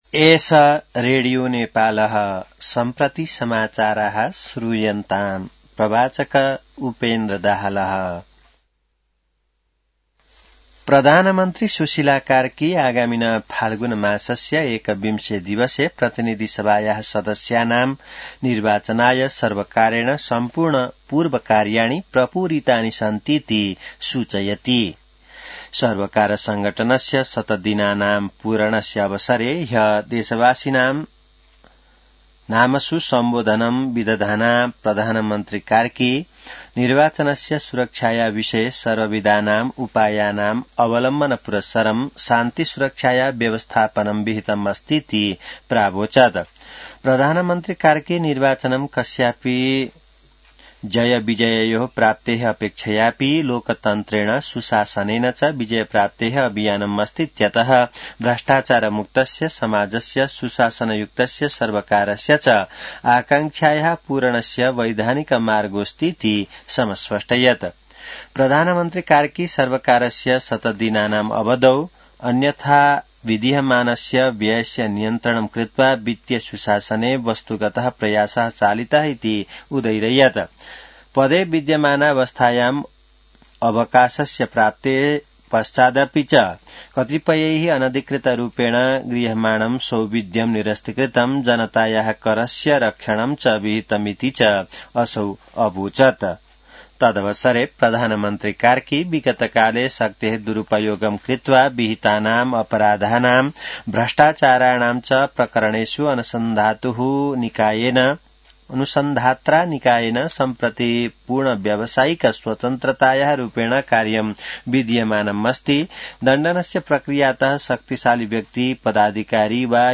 संस्कृत समाचार : ६ पुष , २०८२